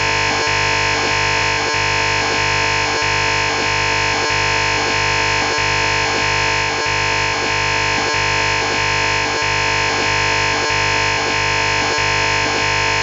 DCCH control channel of an IS-136 (D-AMPS) cellular network with no traffic.
Recorded using 15khz bandwidth and AMAmplitude Modulation demodulation.
IS-136-DCCH.mp3